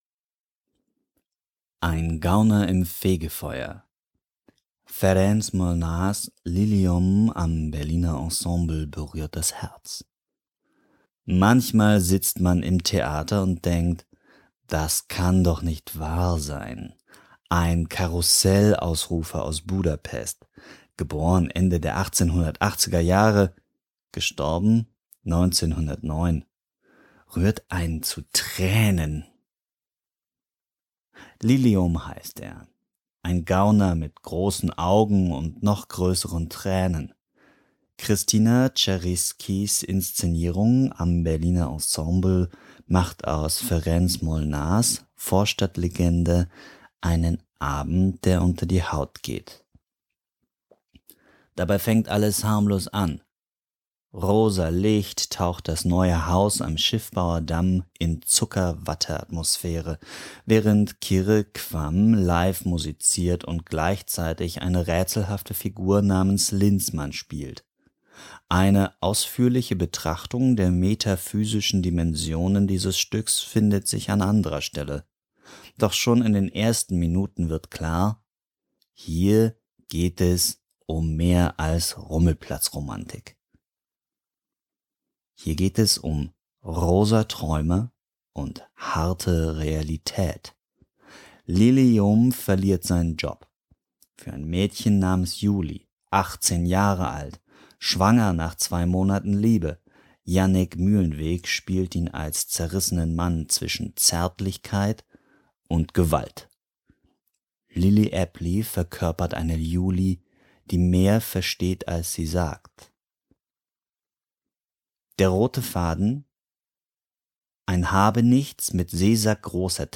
Lesungen und Hörbuch aus Berlin